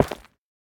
Minecraft Version Minecraft Version snapshot Latest Release | Latest Snapshot snapshot / assets / minecraft / sounds / block / netherrack / break6.ogg Compare With Compare With Latest Release | Latest Snapshot